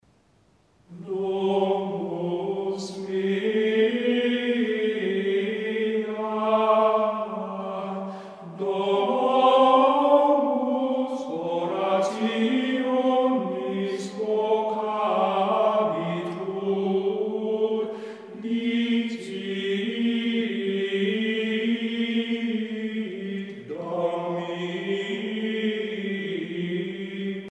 Musique médiévale
Pièce musicale éditée